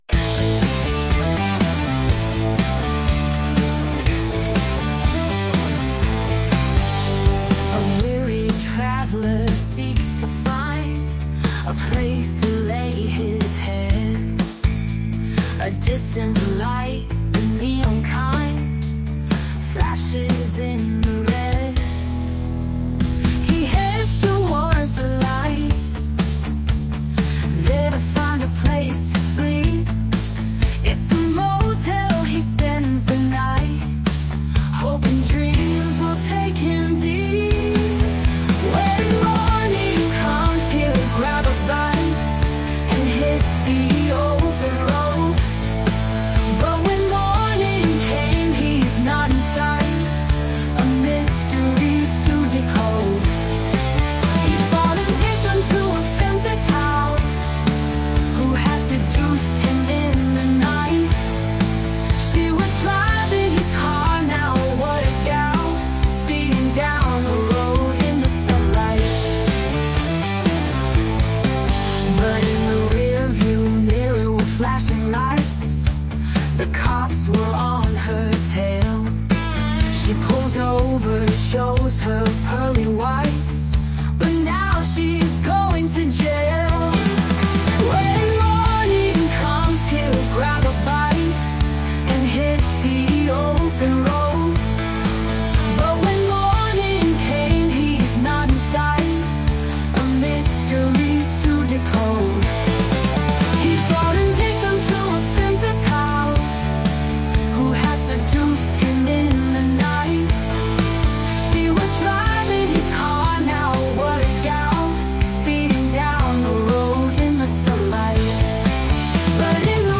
It was a pleasure putting music and voice to your Lyrics.